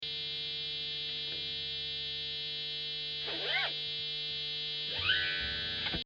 Störgeräusch im Homestudio